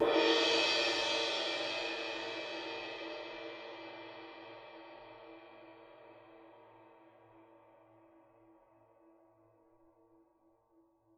susCymb1-hit_f_rr2.wav